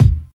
Solid Kick Drum Sound E Key 158.wav
Royality free kick drum sample tuned to the E note. Loudest frequency: 177Hz
solid-kick-drum-sound-e-key-158-v4B.ogg